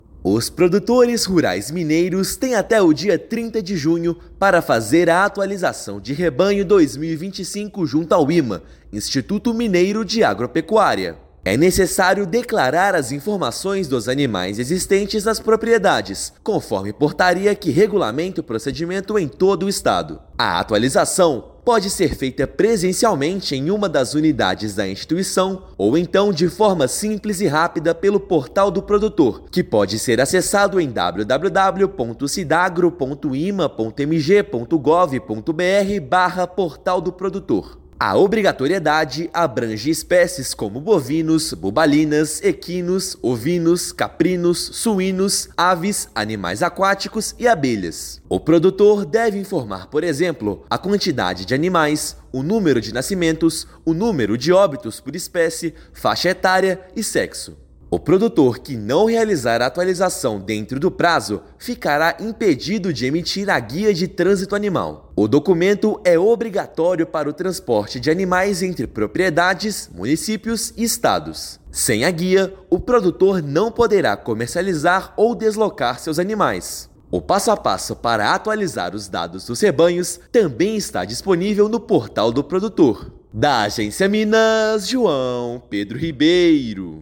Mais de dez espécies, entre elas bovinos, animais aquáticos e abelhas, devem ter os dados atualizados. Ouça matéria de rádio.